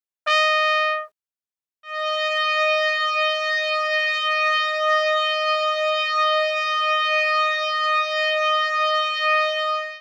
Generate Sustained Trumpet Tone
Could find a trumpet sample and stretch it …